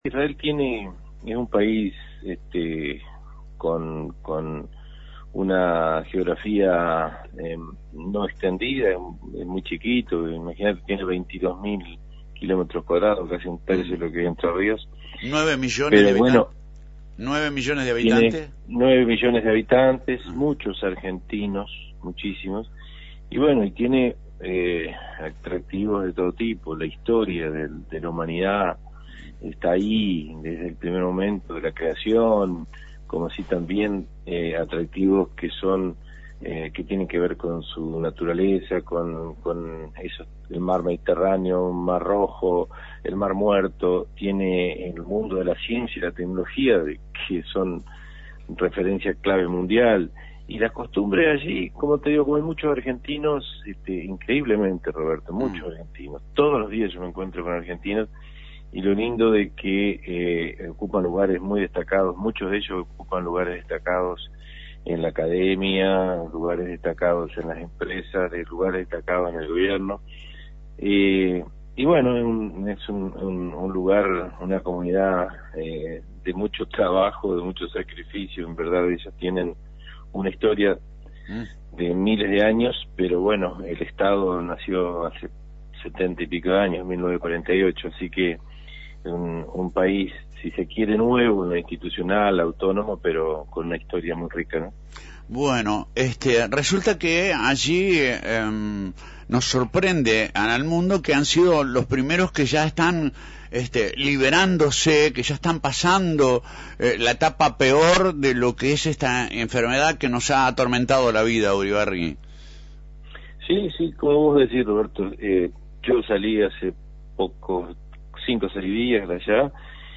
Sergio Uribarri en LT39 RADIO VICTORIA en diálogo con